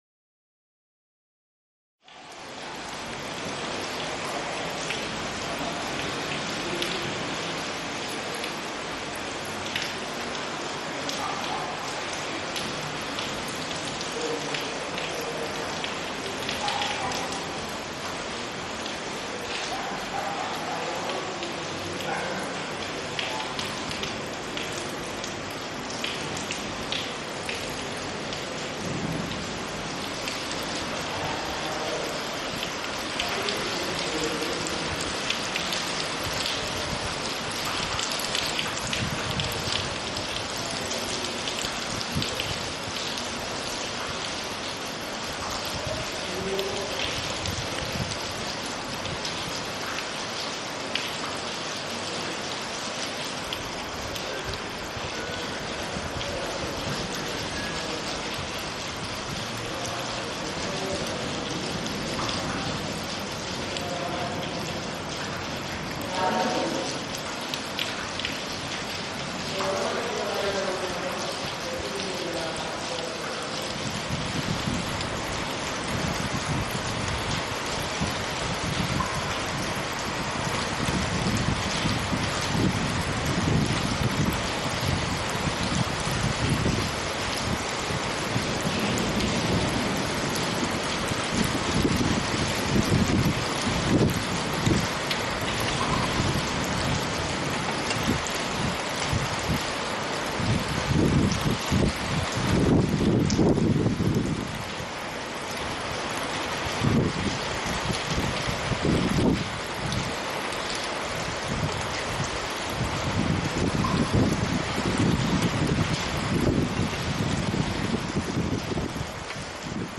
Lluvia en Granada
lluvia_granada.mp3